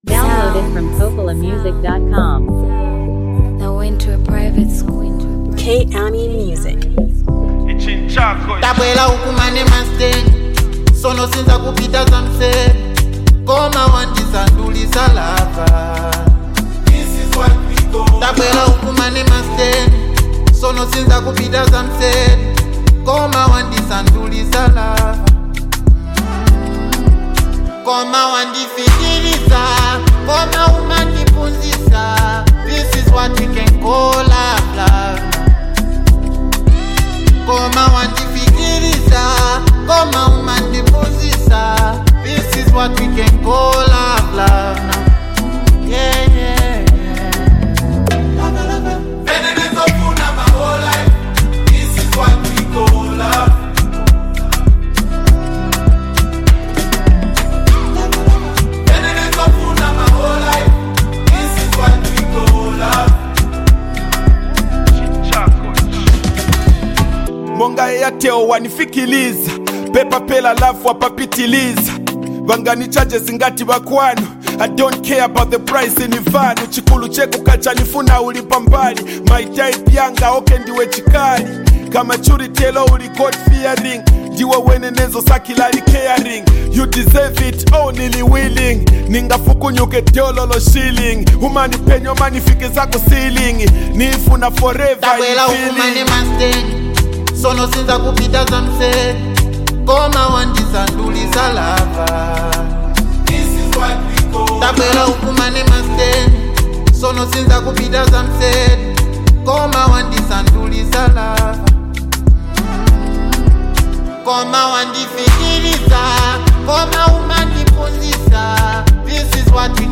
is a powerful and emotionally rich song
a strong gospel-inspired energy